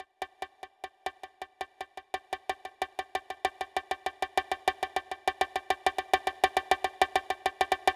J'enregistre sous sonar en midi quelque chose qui se veut être un roulement.
Comme je suis une bille je vous concède que c'est pas super réussi comme roulement... Mais bon, justement, on sent bien les "nuances" de ma maladresse ;).
velocity-128-levels.wav